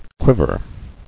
[quiv·er]